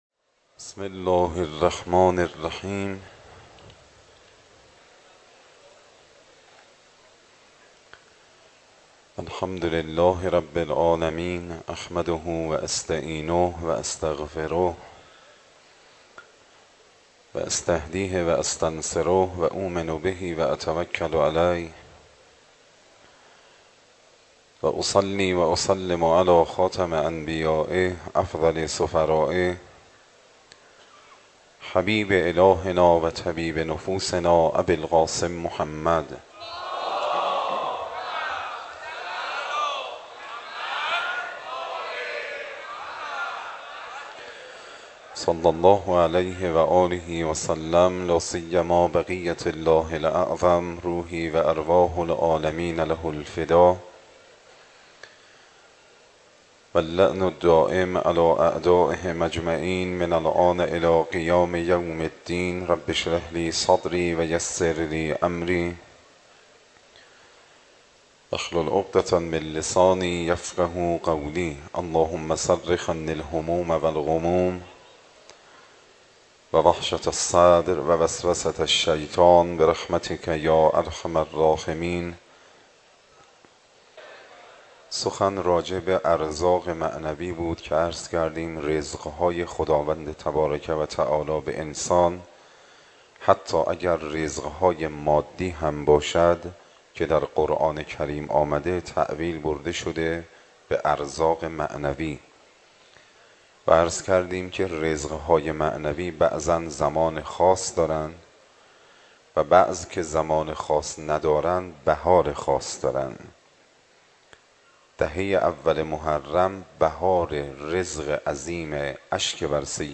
Moharrame 93, Shabe 04, Sokhanrani.mp3